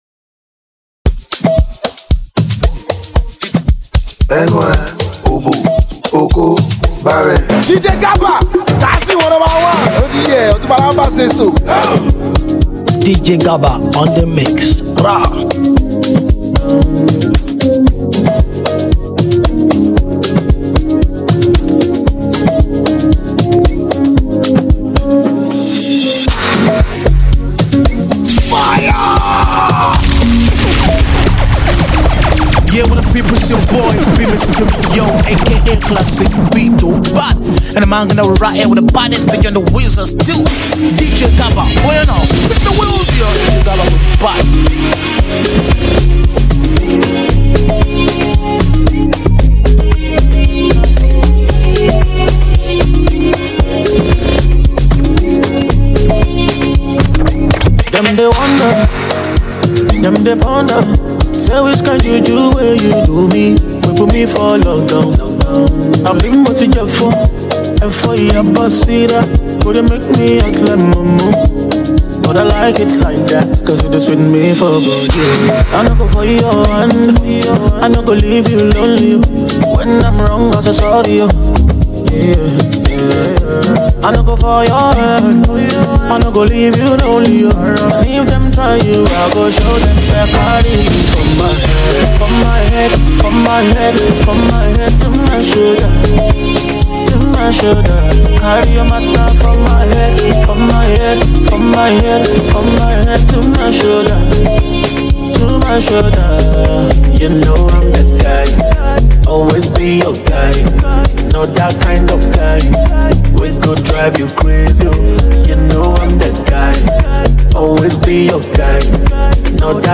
Afrobeat, Street Hop, and Amapiano